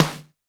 CYCdh_Kurz02-Snr02.wav